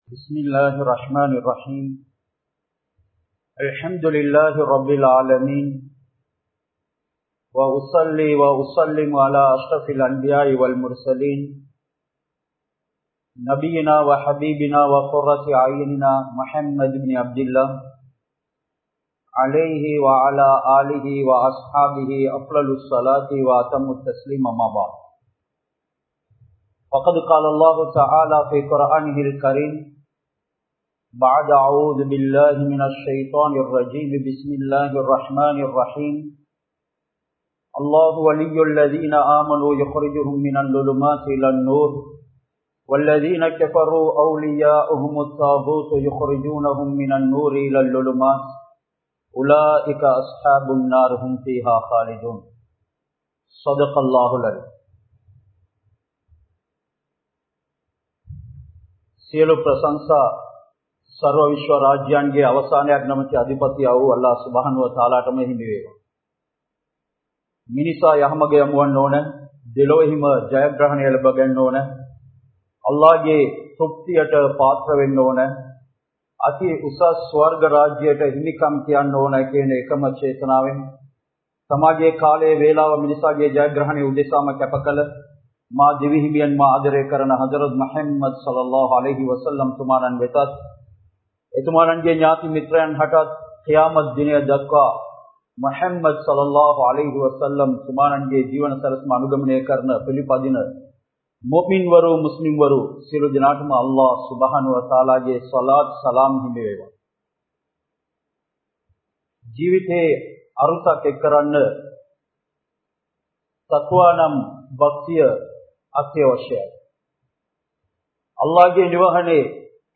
Importance Of Youth | Audio Bayans | All Ceylon Muslim Youth Community | Addalaichenai
Jumua Masjidh